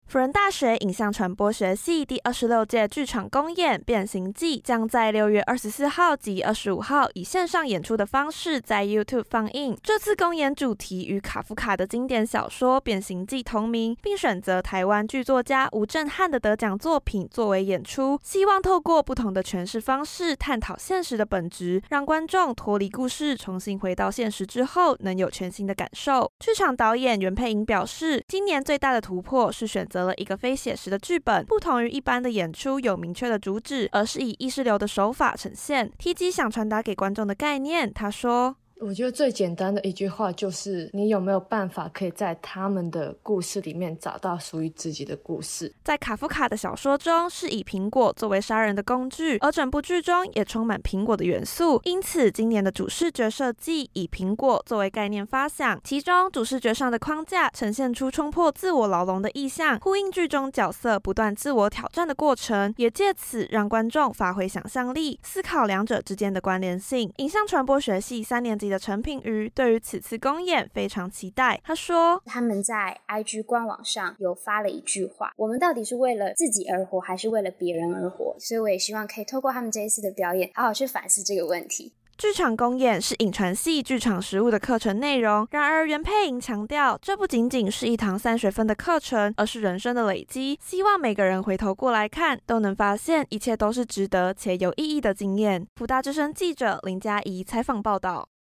採訪報導） 輔仁大學影像傳播學系第二十六屆劇場公演《變形記》，將在六月二十四號及二十五號以線上演出的方式在Youtube放映。